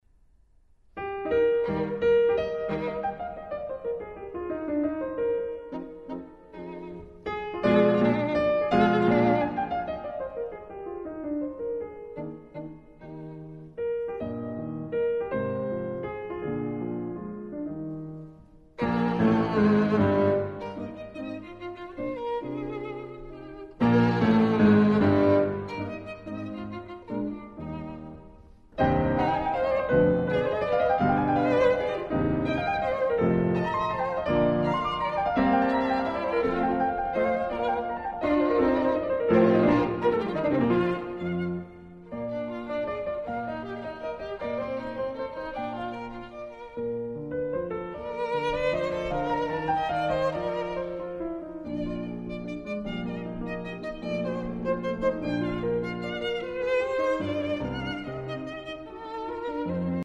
Wolfgang Amadeus Mozart - Sonata in E flat major, K. 481: 1. Molto Allegro